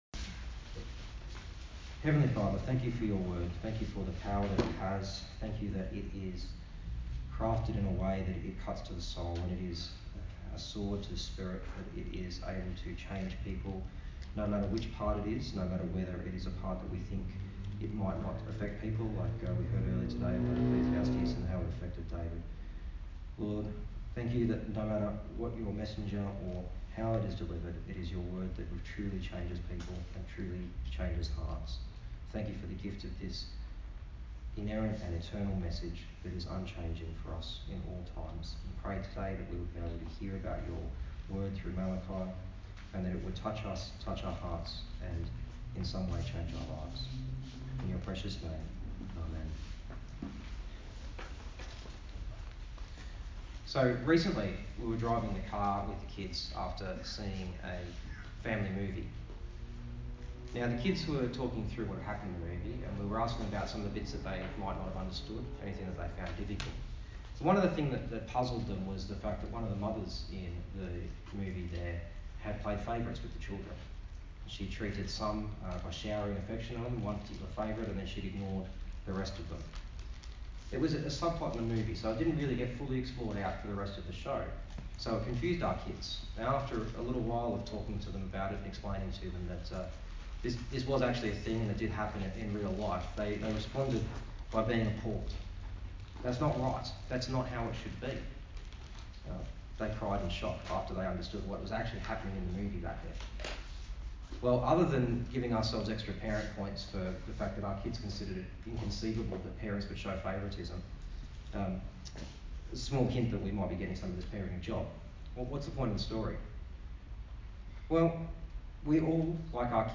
A sermon on the first part of chapter one of Malachi